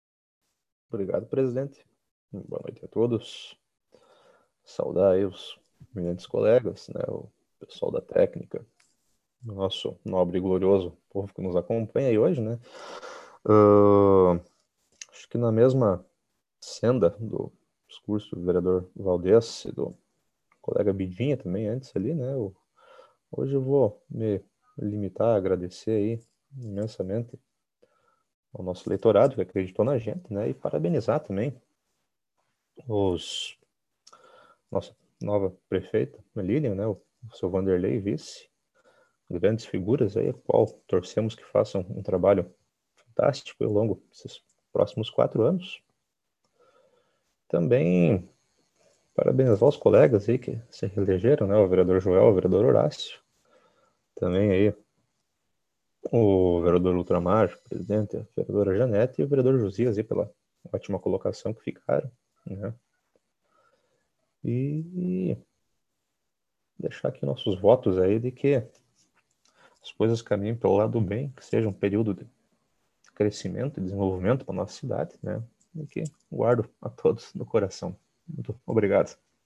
Oradores do Expediente (40ª Ordinária da 4ª Sessão Legislativa da 14ª Legislatura)